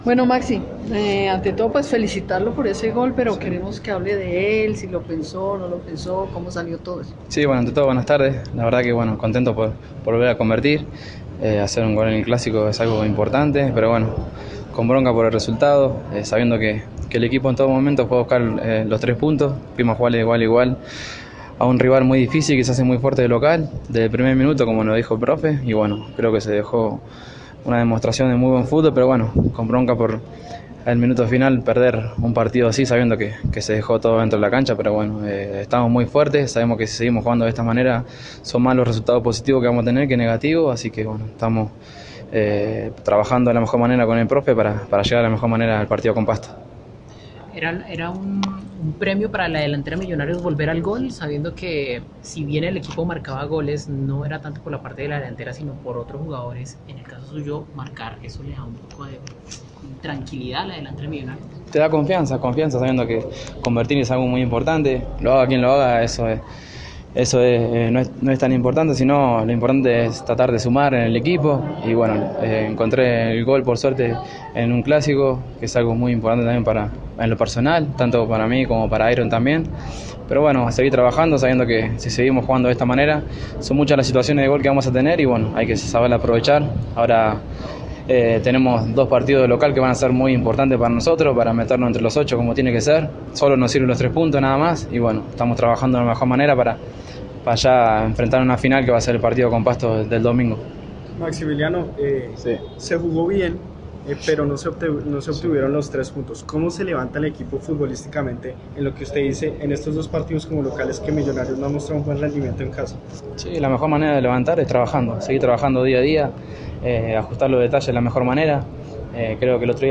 Junto a Miguel Angel Russo y Jair Palacios, Maximiliano Núñez fue el otro jugador que atendió a los medios de comunicación como parte de la previa del partido del próximo domingo ante Deportivo Pasto. El argentino analizó lo que fue el partido contra Nacional y lo que será el duelo ante los del Galeras.